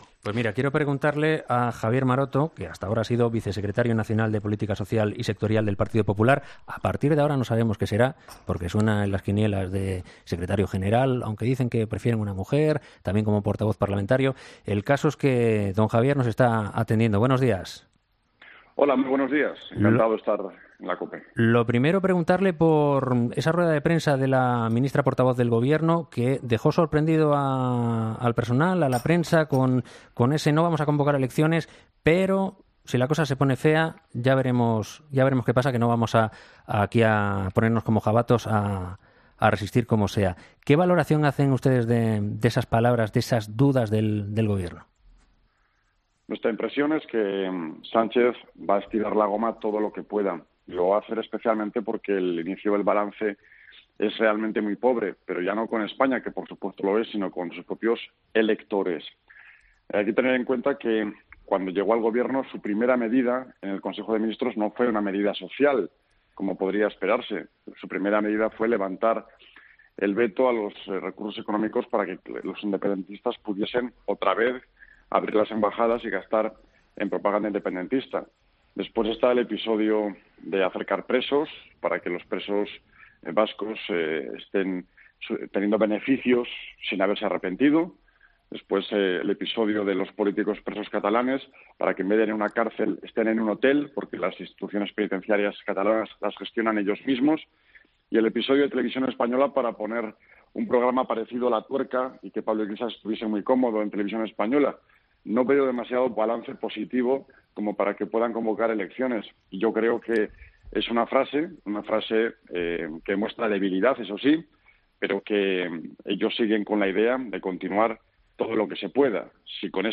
Javier Maroto, vicesecretario nacional de politica social y sectorial del Partido Popular, ha participado en 'Herrera en COPE' para comentar qué supone que Pablo Casado sea el nuevo presidente del PP, tanto a nivel nacional como para el partido.